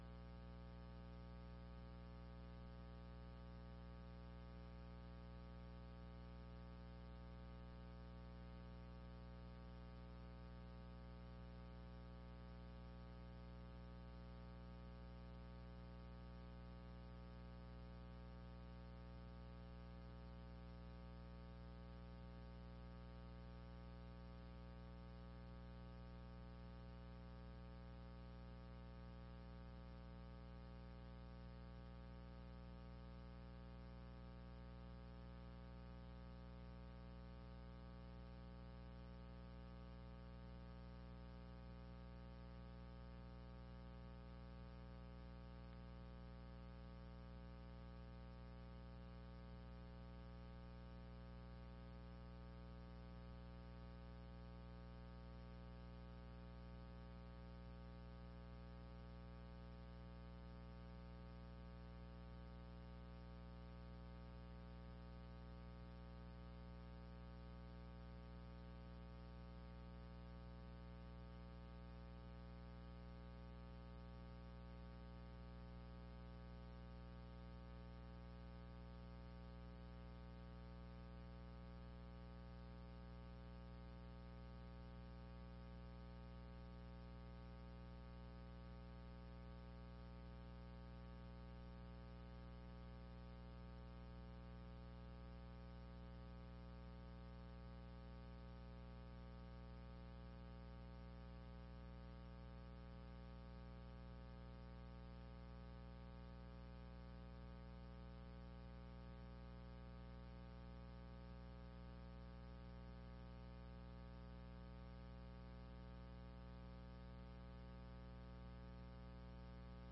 3ª Sessão Extraordinária de 2018